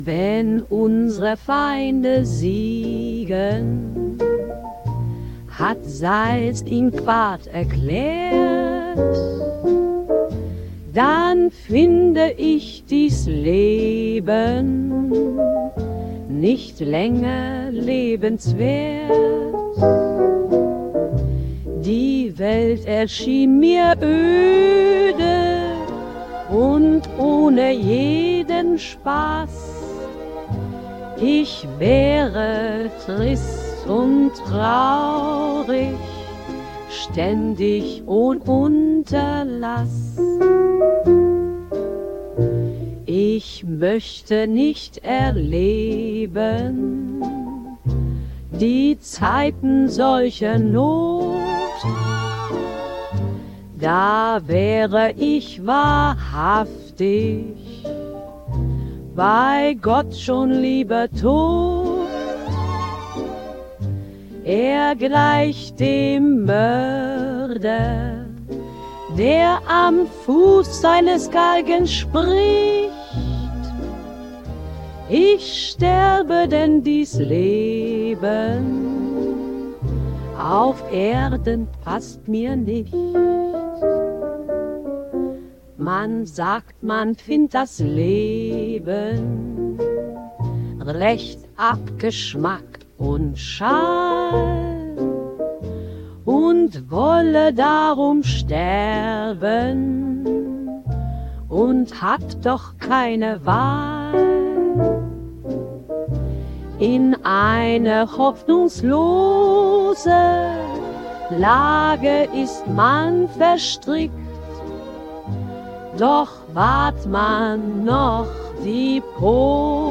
[Musik: Udio Beta / Prompting: Q Kreativgesellschaft, Wiesbaden]